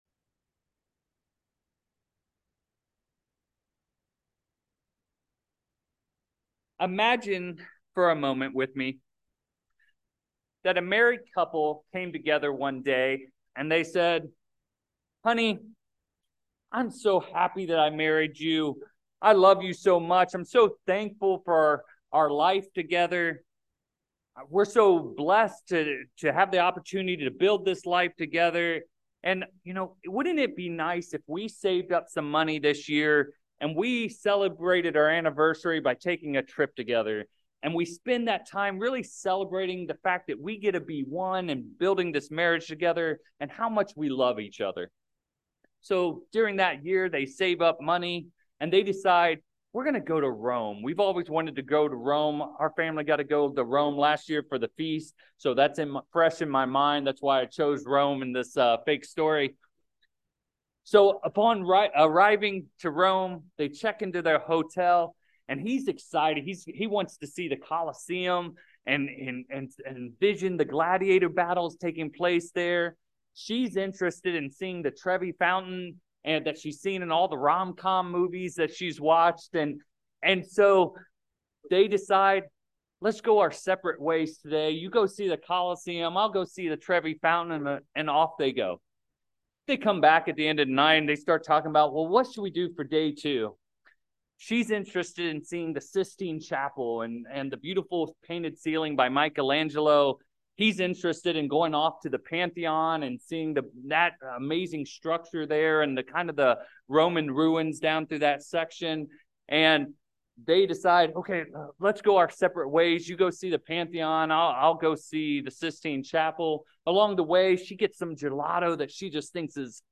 Orinda Is it possible to go and attend the Feast and not please God? In this sermon, we will look at four aspects from Deuteronomy that will help us have a purpose-driven Feast.